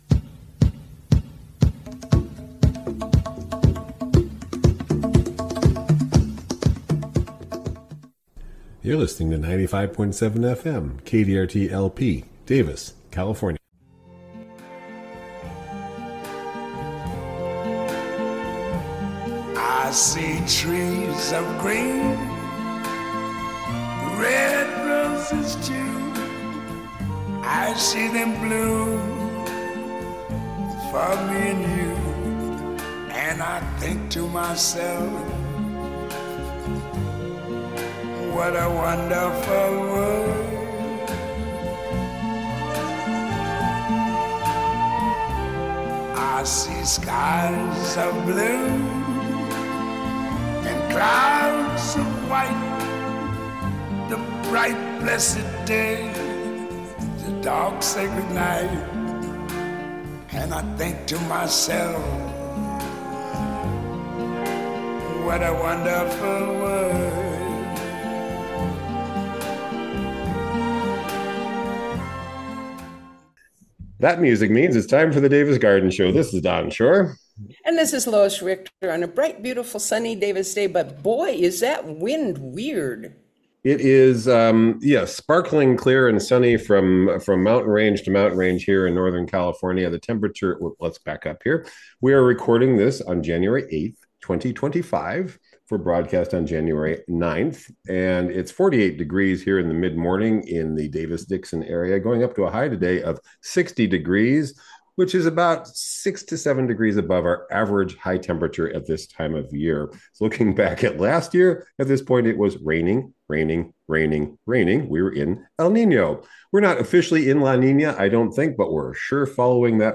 Winter gardening conversations